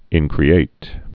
(ĭnkrē-āt, ĭn-krēĭt)